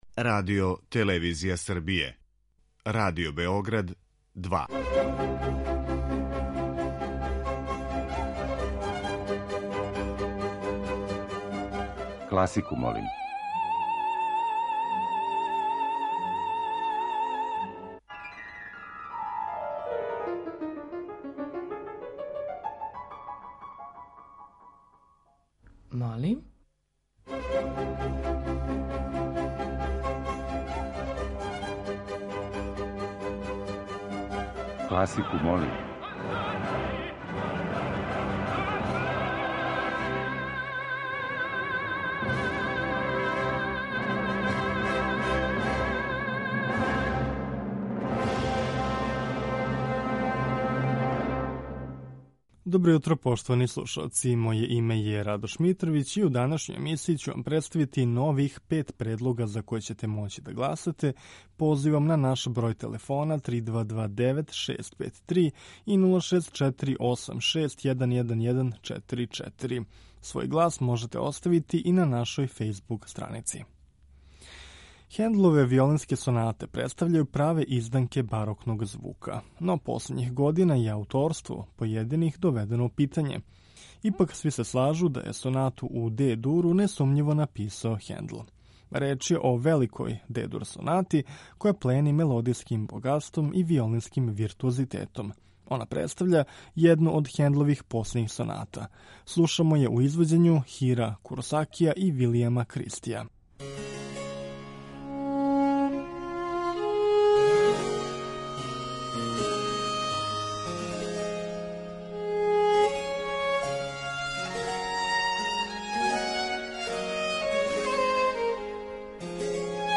У емисији Класику, молим ове недеље окосница ће нам бити увертире познатих или мање познатих оперских остварења.